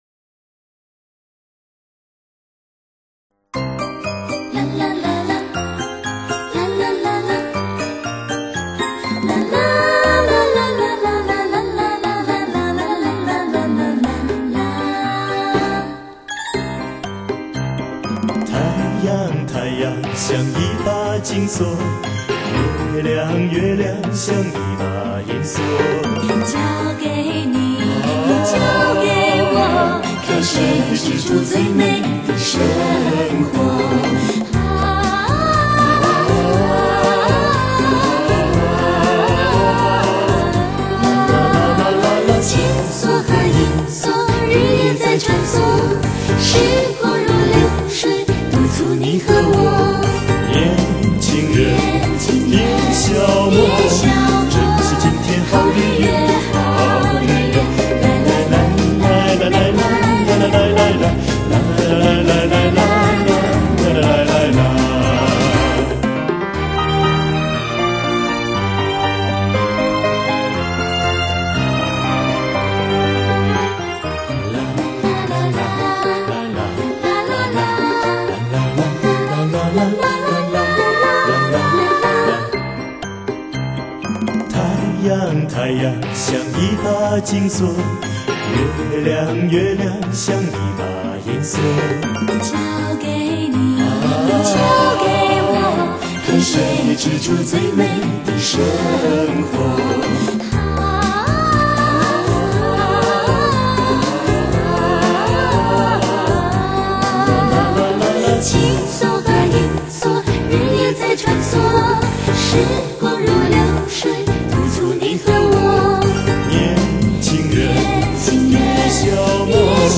錄音機：TASCAMA-80 24軌糢擬錄音機 MIC:U-87 非常廣泛使用的一種電容麥尅風